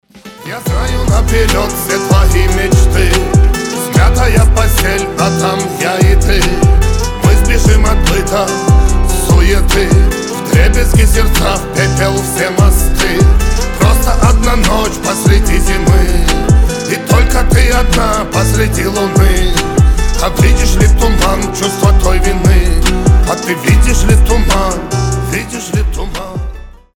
• Качество: 320, Stereo
лирика
дуэт